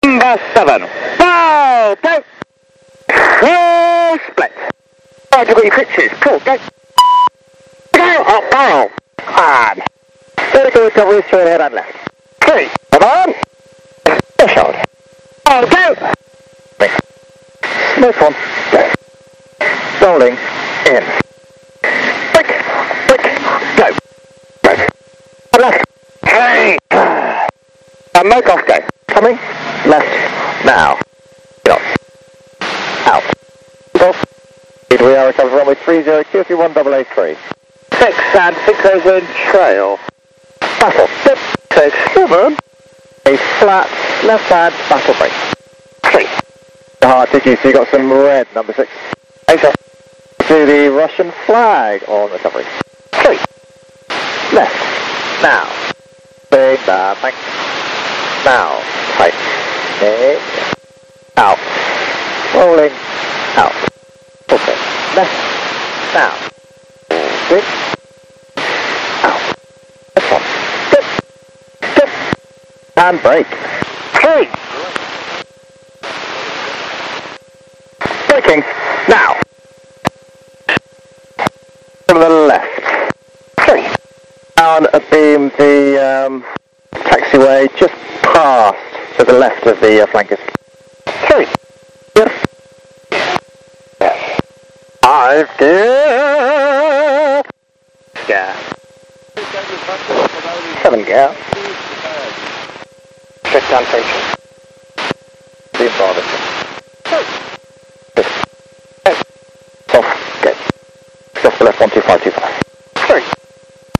Начало » Записи » Записи радиопереговоров - авиация
Дикие вопли группы "Red Arrows" в небе Жуковского.